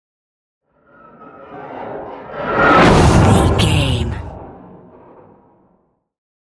Dramatic whoosh to hit trailer
Sound Effects
Atonal
intense
tension
woosh to hit